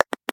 pause-back-click.ogg